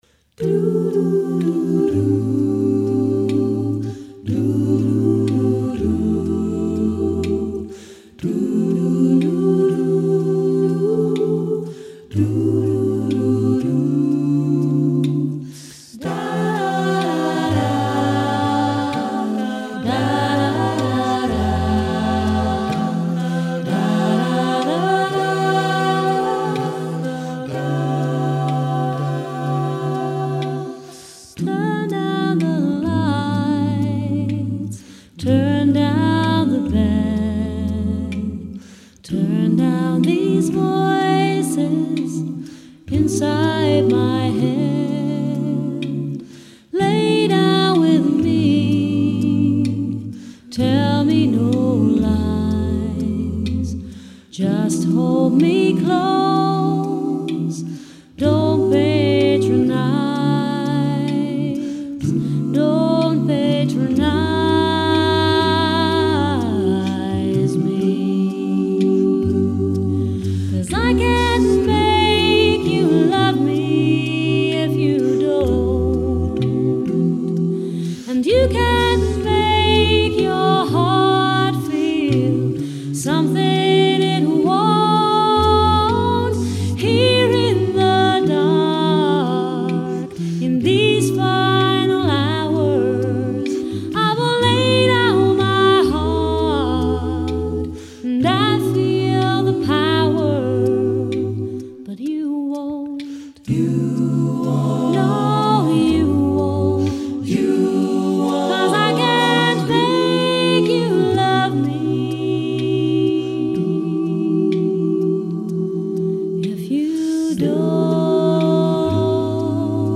Une belle ballade sur l’amour impossible